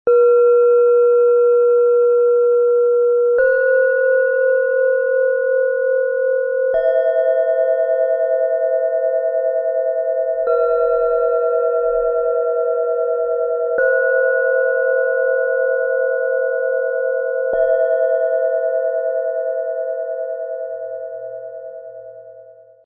Ruhe, Harmonie & Licht - Set aus 3 Klangschalen in schwarz-gold, Ø 11,1 - 12,4 cm, 1,16 kg
Dieses Set aus drei schwarz-goldenen Klangschalen vereint beruhigende Tiefe, sanfte Harmonie und lichtvolle Klarheit.
Ihr tiefer, voller Klang bringt Stabilität & Ausgleich.
Ihr Klang ist sanft, einhüllend und harmonisierend.
Ihr heller, freundlicher Ton wirkt befreiend und inspirierend.
Im Sound-Player - Jetzt reinhören kannst du den Original-Ton genau dieser Schalen anhören und ihre harmonischen Schwingungen erleben.
MaterialBronze